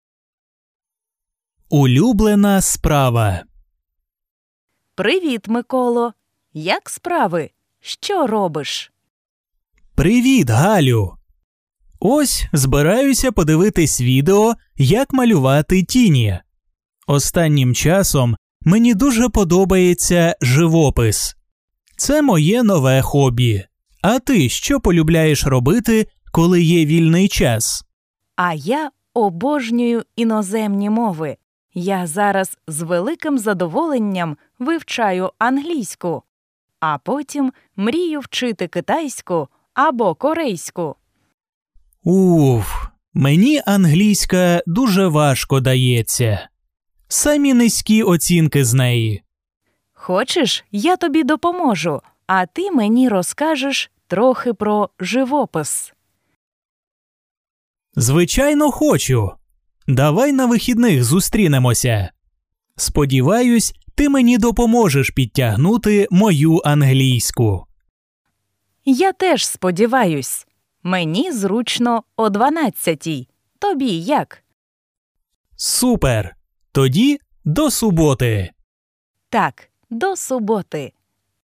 Dialogues with audio
dialogue-elementary-hobby.mp3